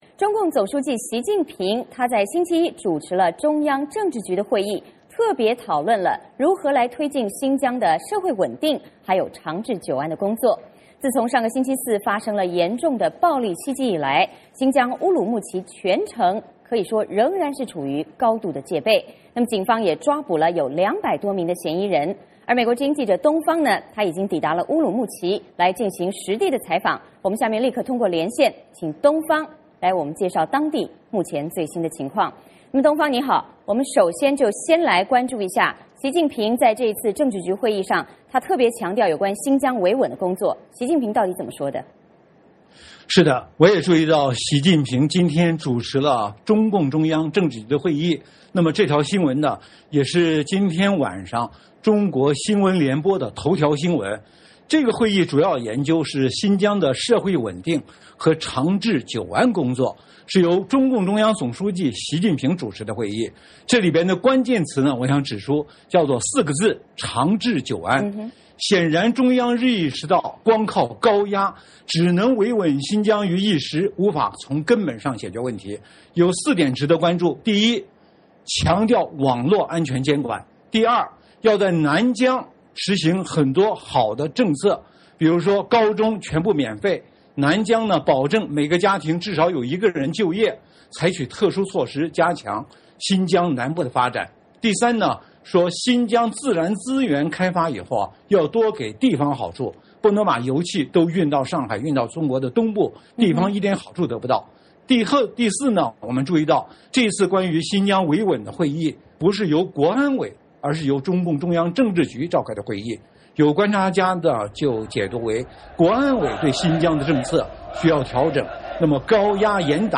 VOA连线：现场观察：522袭击后，新疆警方逮捕两百多人